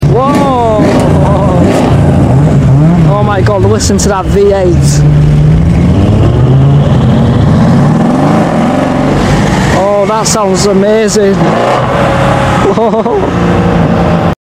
W212 E63 AMG Loud V8 sound effects free download
W212 E63 AMG Loud V8 Revs And Exhaust Sounds!!